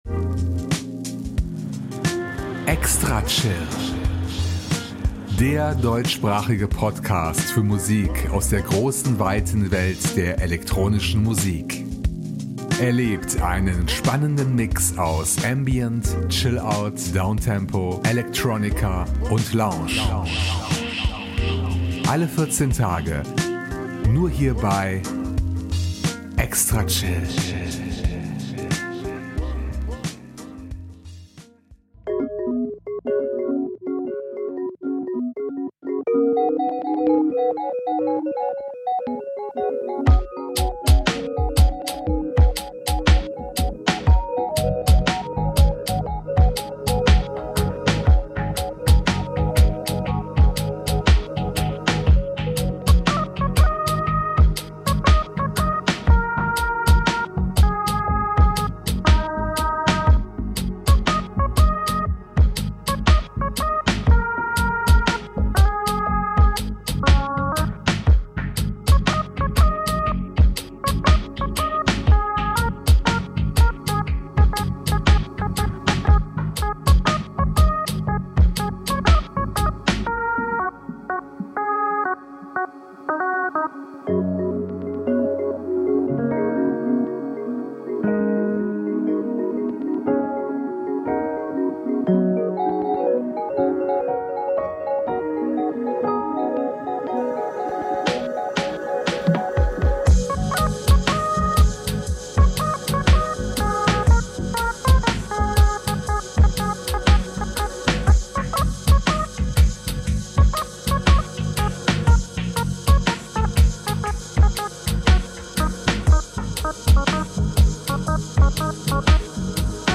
mit einem gewohnt erstklassigen Electronica-Mix.